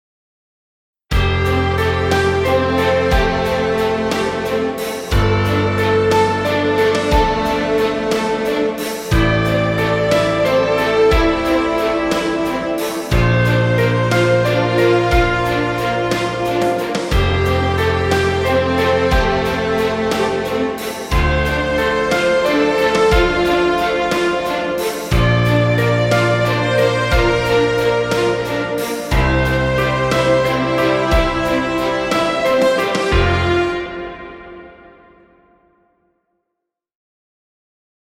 cinematic dramatic music. Epic orchestral track.
Cinematic dramatic music. Trailer music.